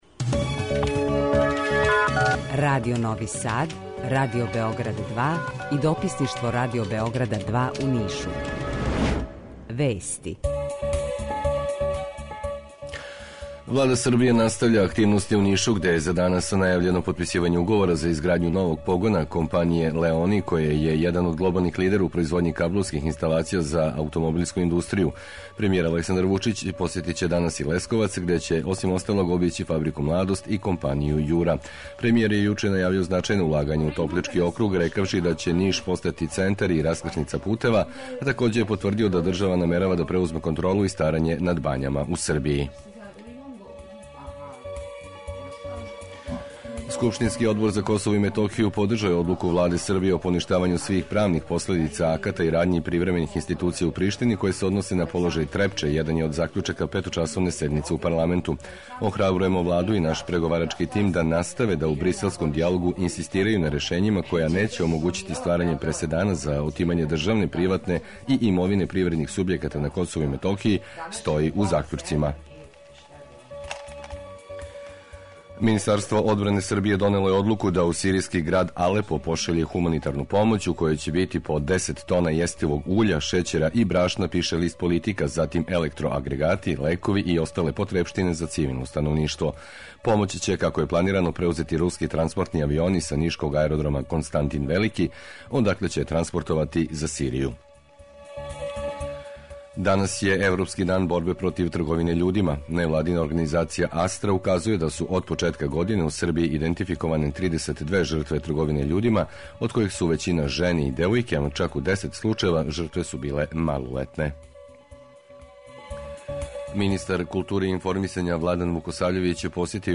Jутарњи програм заједнички реализују Радио Београд 2, Радио Нови Сад и дописништво Радио Београда из Ниша.
Наш репортер јавиће се са леве обале Дунава јер становници насеља са те обале и Панчевци крећу раније на посао.